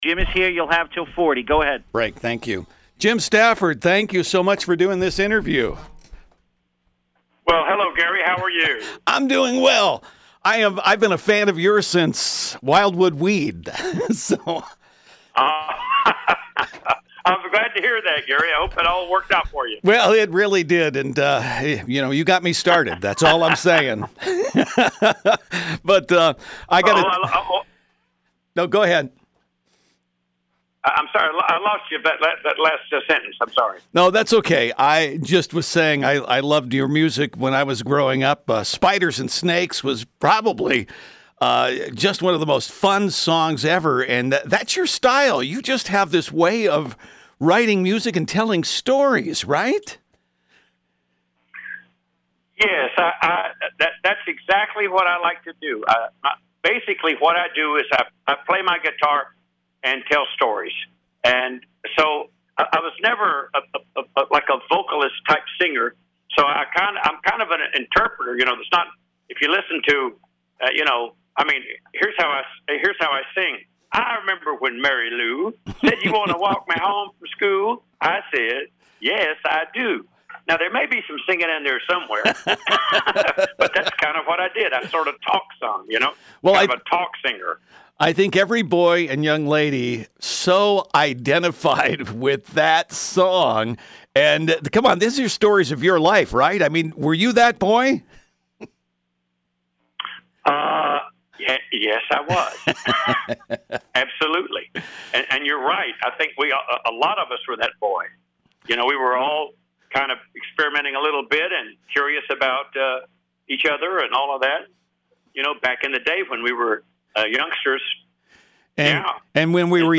spent a few minutes on the phone with entertainer Jim Stafford.